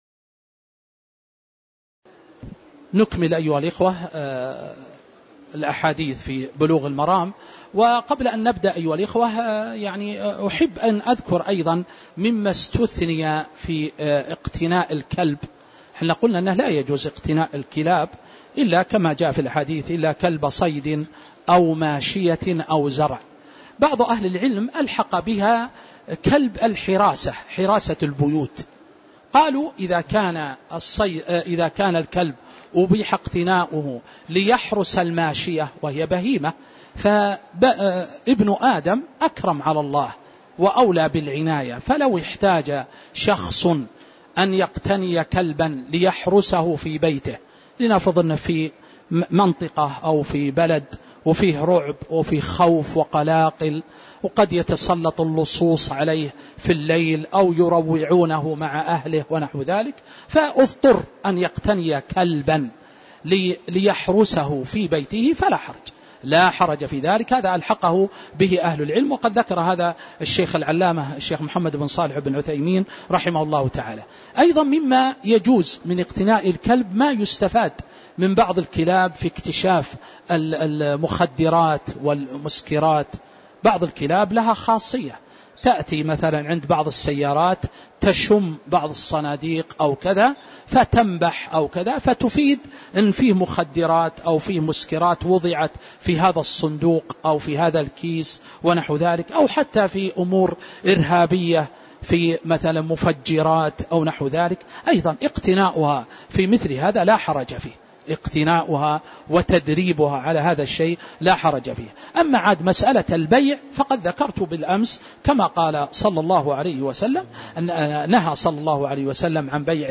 تاريخ النشر ٢٧ ربيع الأول ١٤٤٦ هـ المكان: المسجد النبوي الشيخ